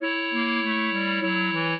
clarinet
minuet10-11.wav